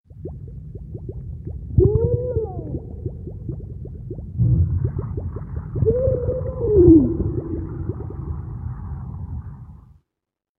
На этой странице собраны звуки тонущего человека в разных ситуациях: паника, борьба за жизнь, захлебывание водой.
Звук ребенка тонущего в воде